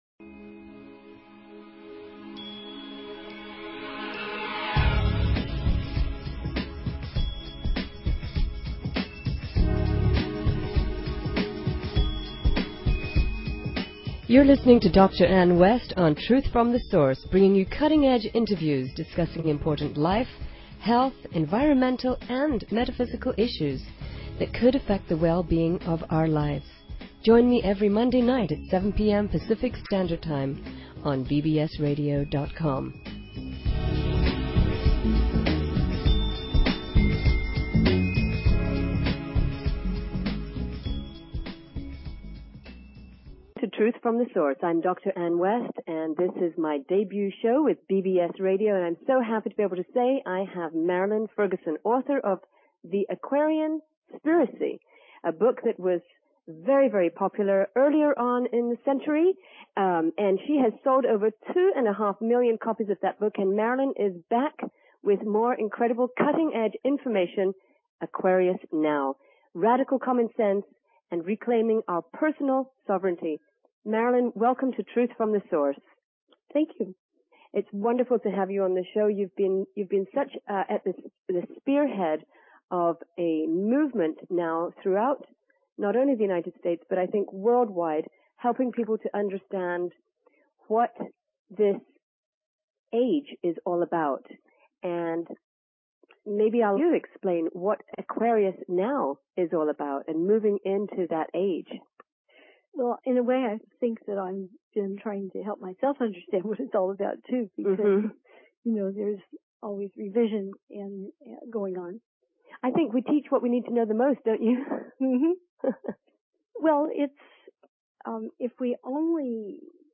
Talk Show Episode, Audio Podcast, Truth_From_Source and Courtesy of BBS Radio on , show guests , about , categorized as